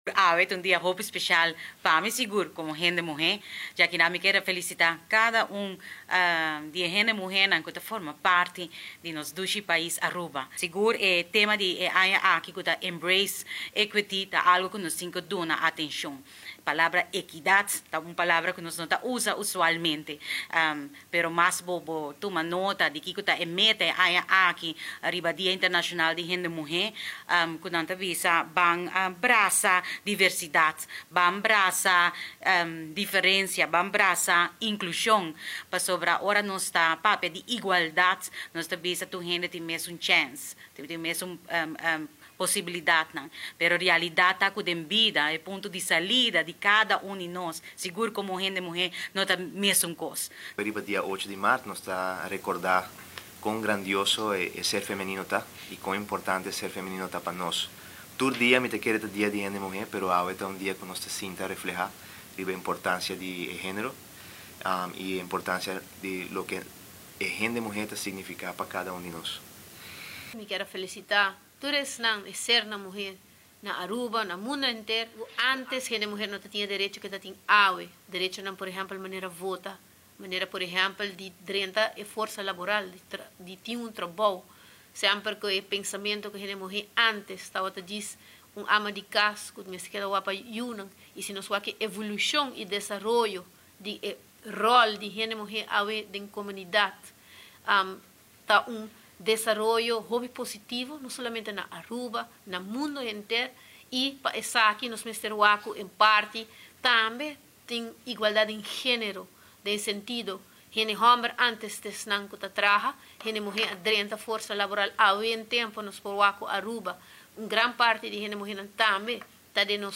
Den conferencia di MEP Parlamentario Shailiny Tromp Lee, Arthur Vallejo y Setty Yarzagaray a felisita tur hende muhe riba nan dia.